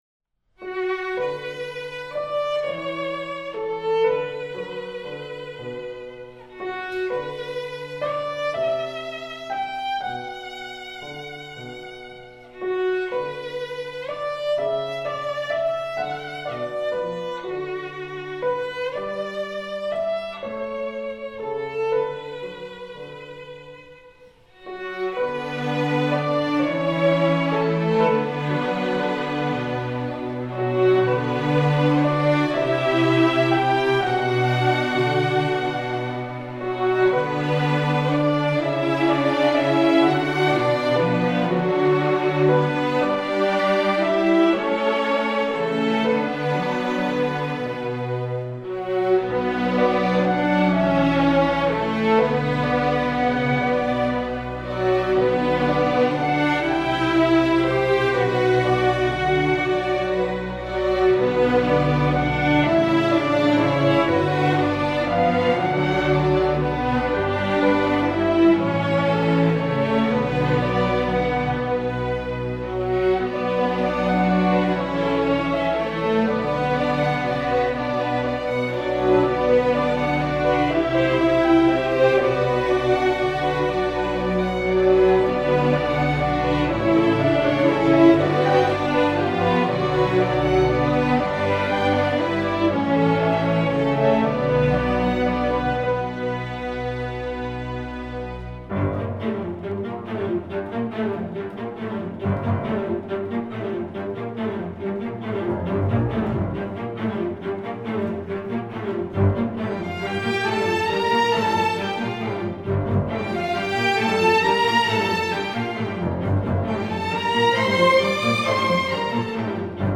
classical, instructional, children